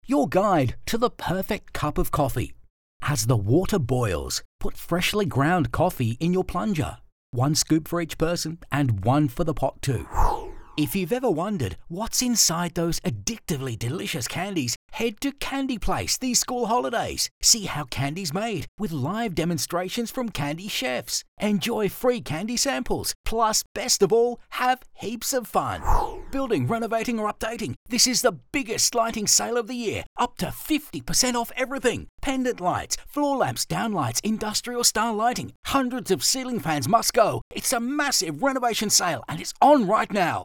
• Natural Conversational
• Middle age male voiceovers
• Custom Voice Booth
• Microphone Neumann TLM 103